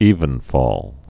(ēvən-fôl)